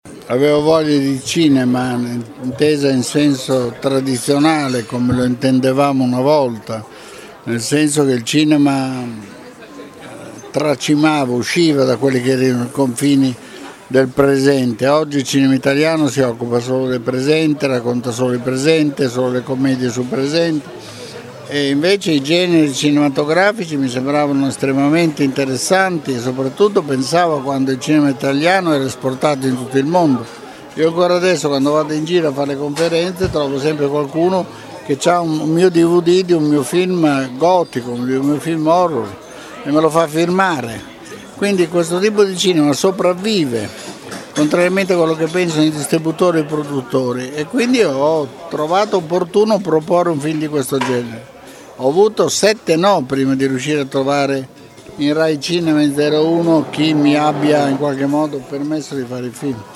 Intervista al Maestro Pupi Avati